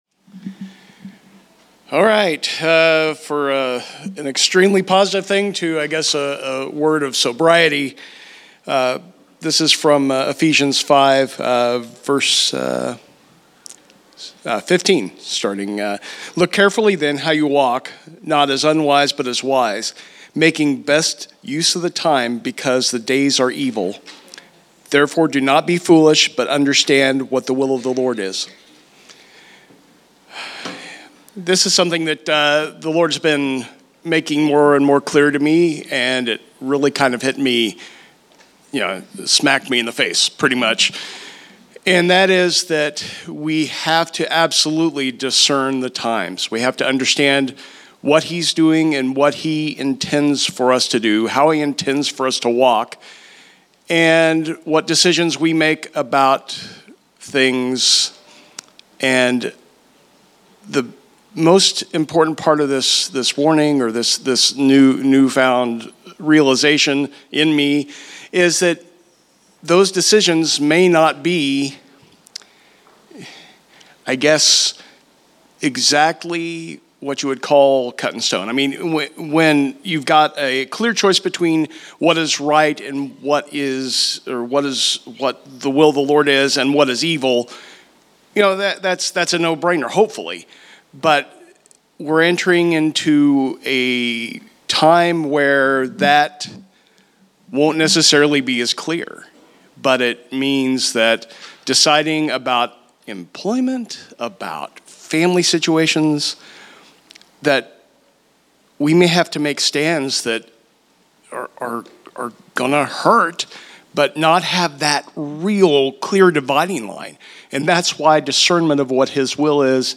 Category: Exhortation